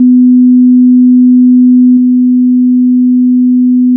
《基準壁》からの音 2秒 →《基準壁+ノイズクリア》からの音 2秒
- 固体伝播音の場合 -
( スピーカーを壁に直付け固定して測定 )